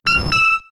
Cri de Mélodelfe K.O. dans Pokémon X et Y.